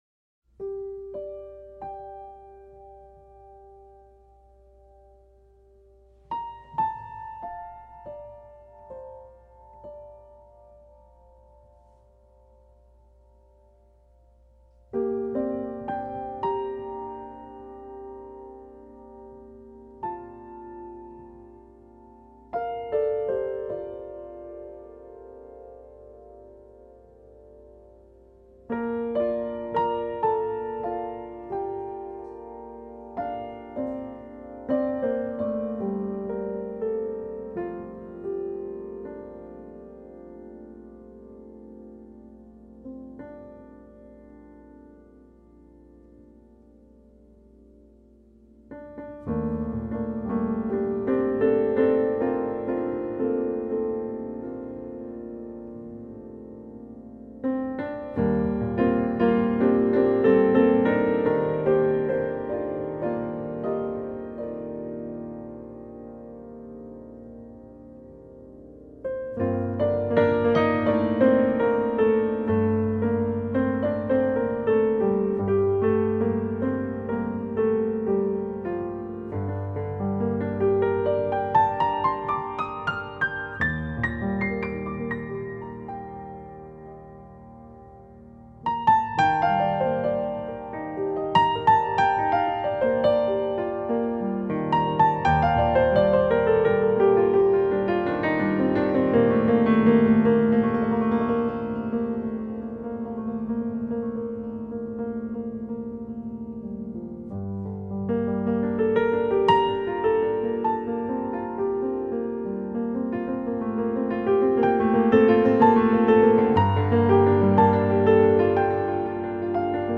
آهنگی زیبا و آرامبخش از این پیانیست توانا وخوش نواز
نوع آهنگ: لایت]